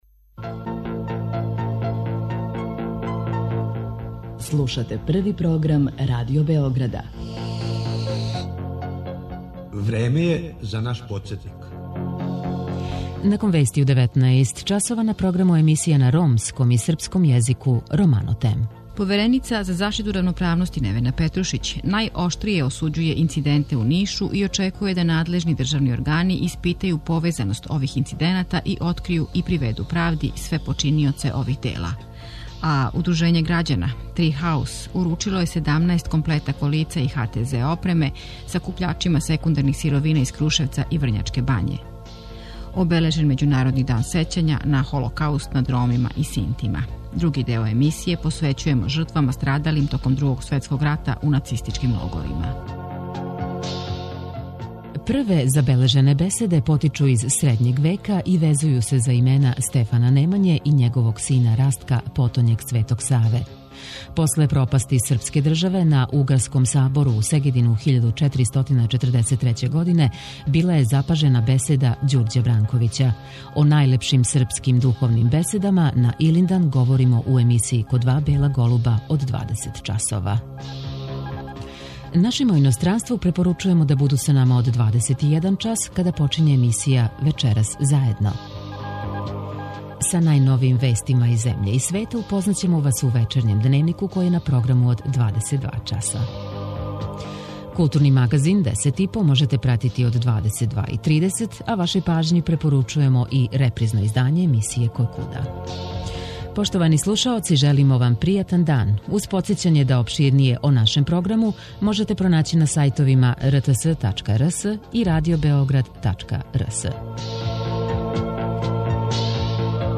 Са Гитаријаде за Радио Београд 1 извештава, у оквиру емисије Поп Карусел и кроз програм током првог августовског викенда